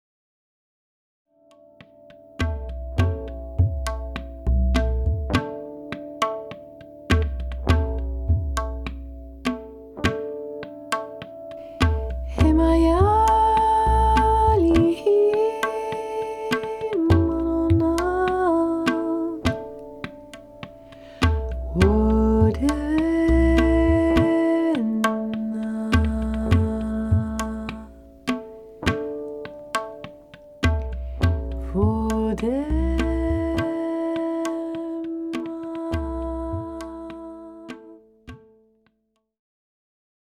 heartwarming sounds